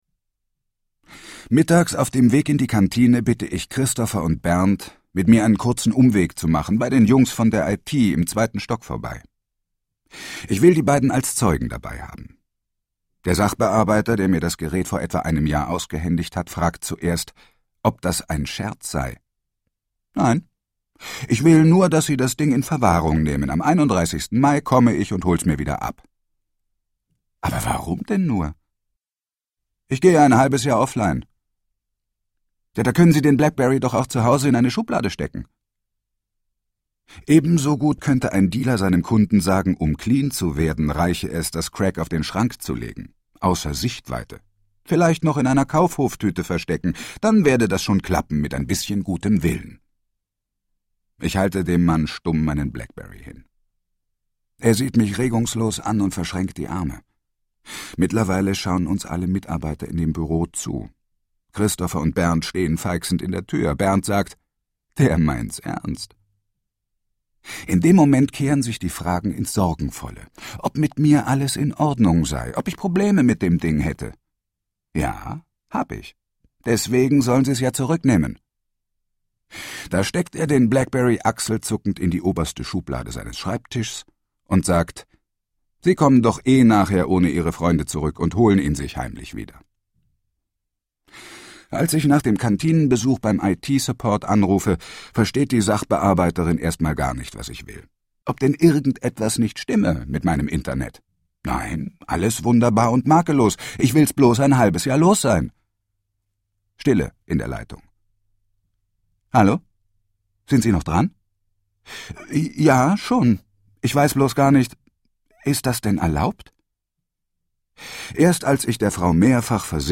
Ein amüsantes Hörbuch voller Selbstironie und einer klaren Botschaft: Wenn das Netz aus ist, geht etwas anderes an: das eigene Gehirn.